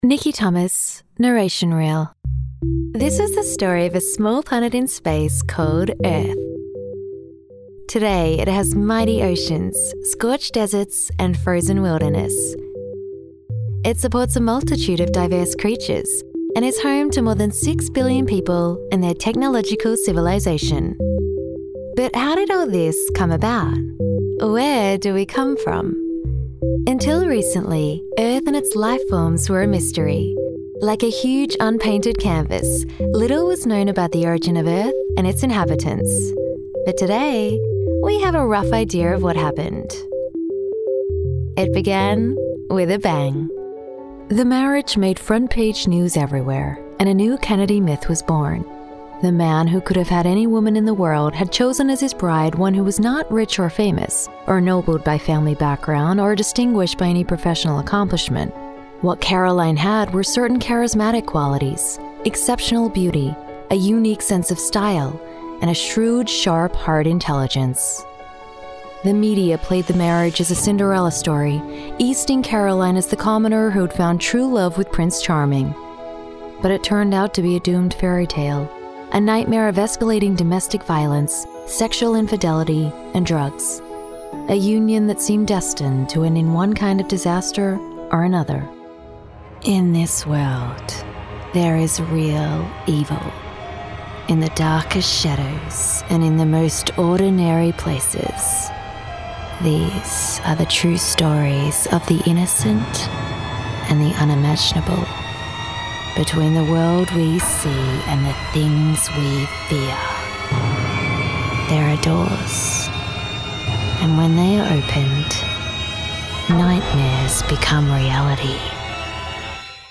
Friendly, upbeat
englisch (australisch)
Sprechprobe: Industrie (Muttersprache):
Female VO Artist